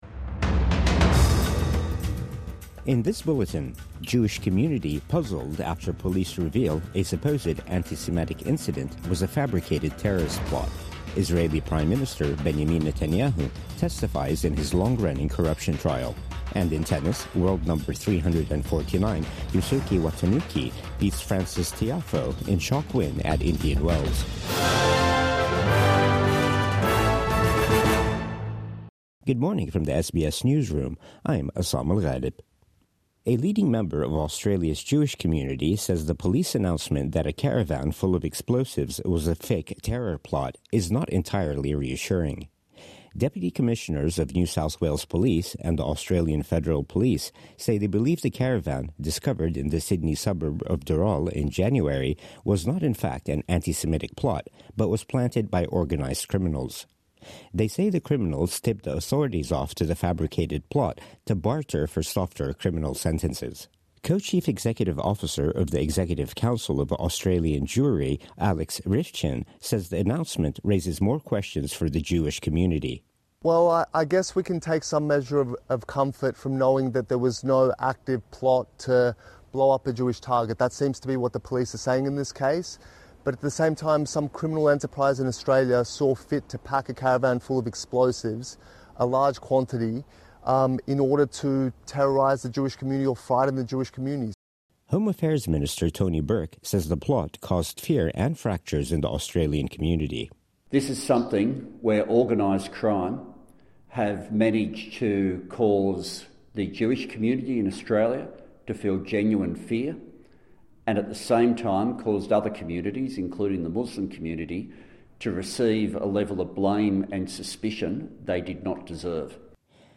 Supposed antisemitic incident confirmed as 'fabricated terrorist plot' | Morning News Bulletin 11 March 2025